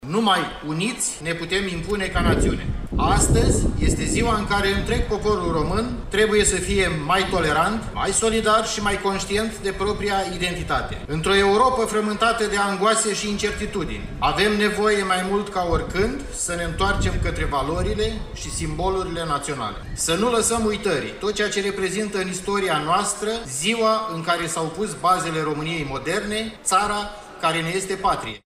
La rândul său, prefectul județului Iași, Dan Cârlan, a vorbit despre importanța momentului sărbătorit astăzi: